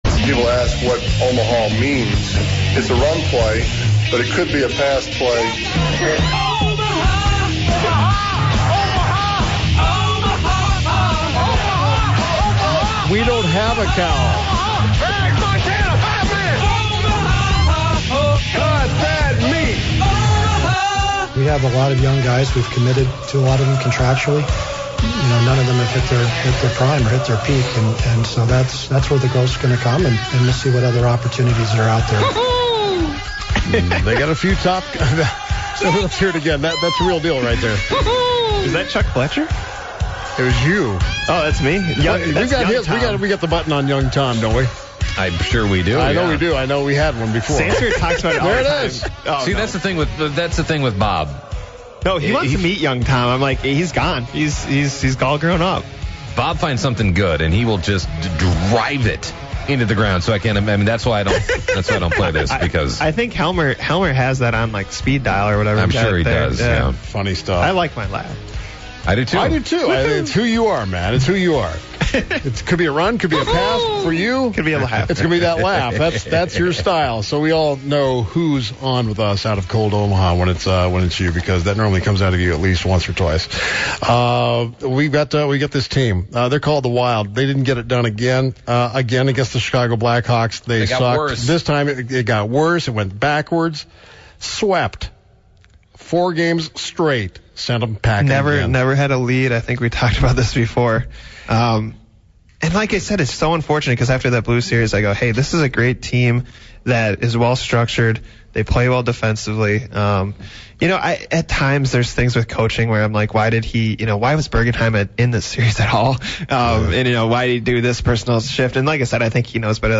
play NFL Scandal Trivia with callers for prizes